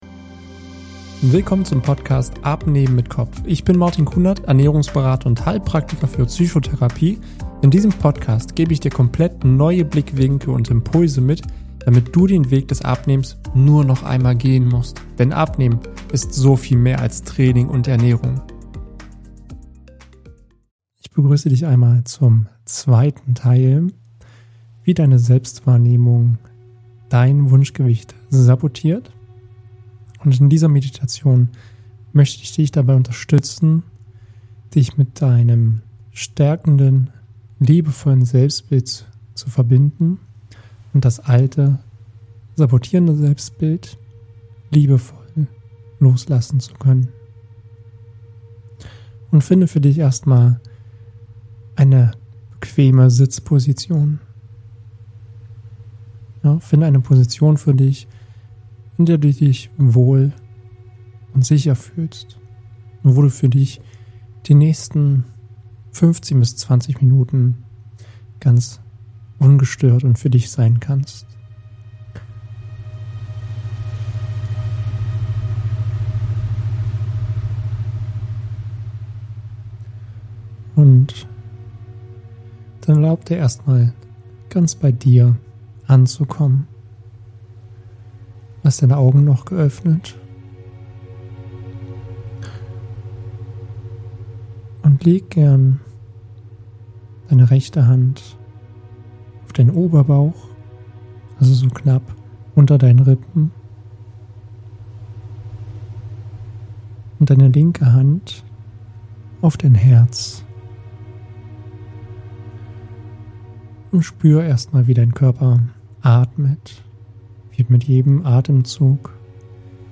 Ich sehe mich selbst in neuem Licht (geführte Meditation) 2.Teil ~ Abnehmen mit Kopf - Der Podcast für mehr Wohlbefinden & Leichtigkeit im Alltag Podcast